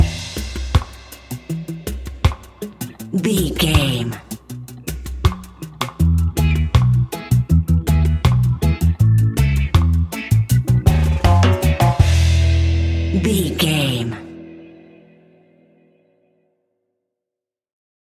Classic reggae music with that skank bounce reggae feeling.
Ionian/Major
reggae instrumentals
laid back
chilled
off beat
drums
skank guitar
hammond organ
percussion
horns